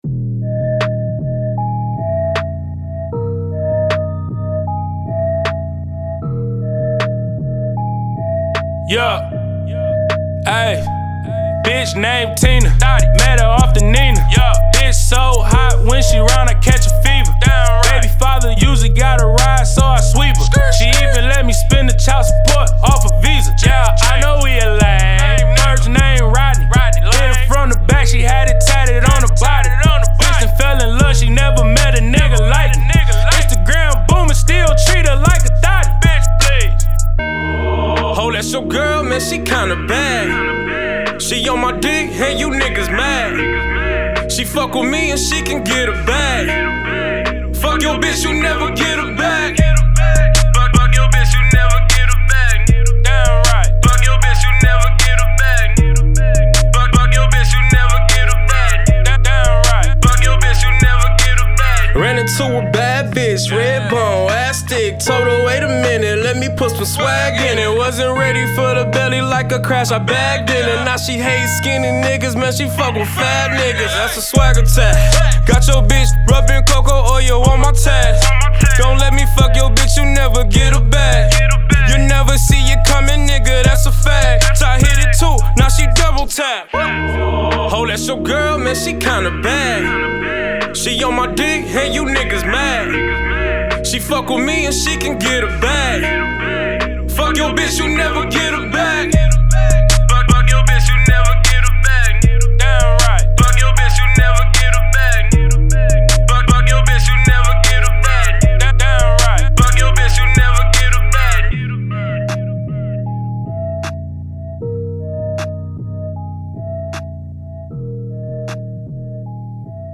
Hiphop
a catchy song